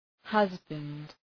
Προφορά
{‘hʌzbənd}